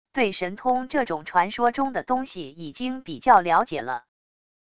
对神通这种传说中的东西已经比较了解了wav音频生成系统WAV Audio Player